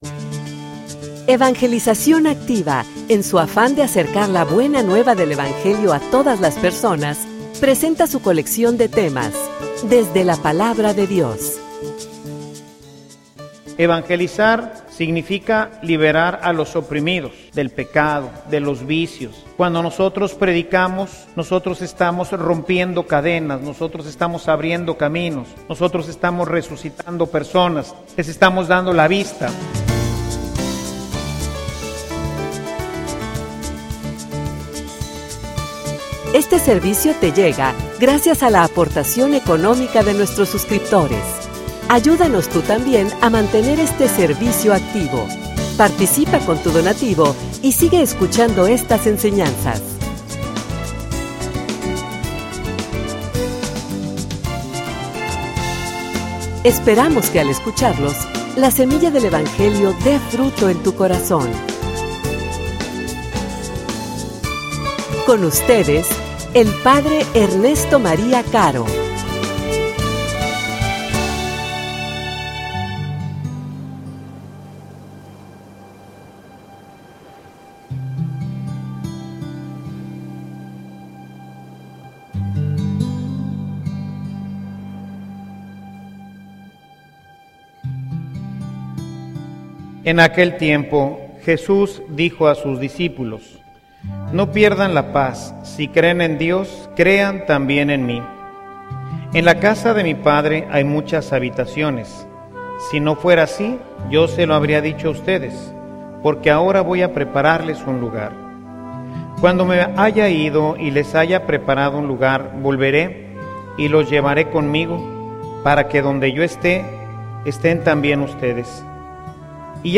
homilia_Creer_y_actuar_como_Cristo.mp3